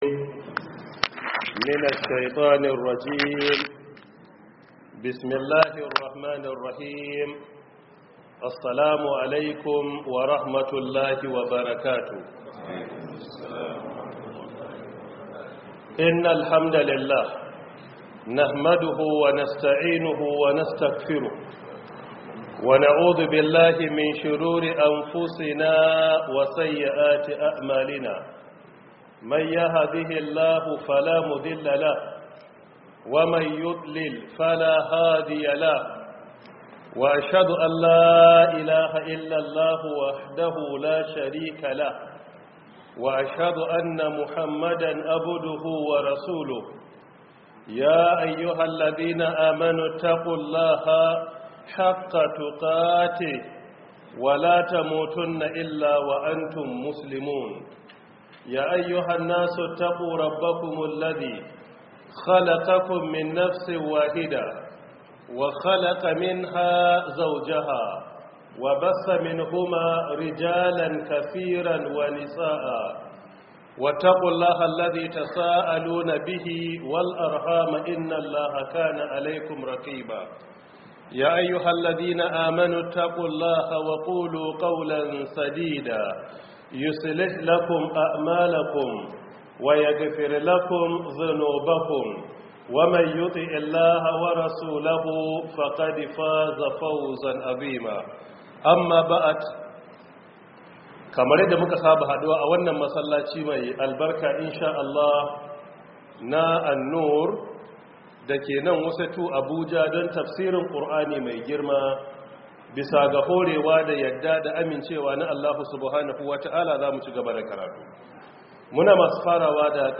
Download Audio From Book: 1447/2026 Ramadan Tafsir